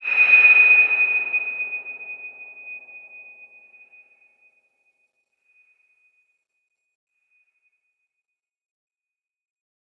X_BasicBells-D#5-pp.wav